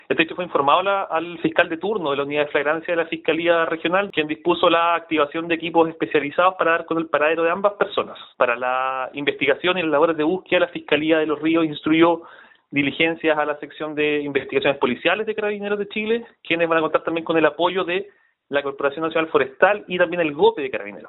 El fiscal de turno, Joaquín Ramírez, indicó que solicitaron la colaboración de Carabineros y la Corporación Nacional Forestal.
cu-extraviados-1-fiscal.mp3